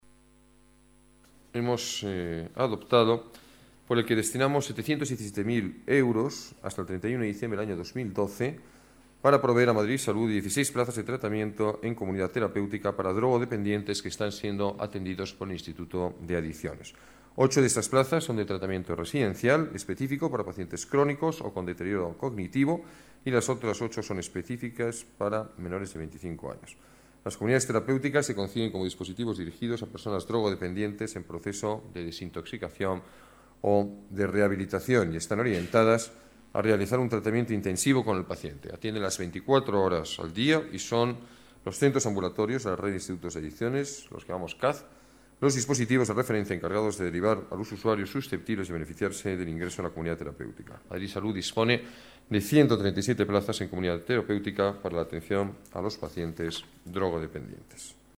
Nueva ventana:Declaraciones del alcalde, Alberto Ruiz-Gallardón: Recuperación Toxicómanos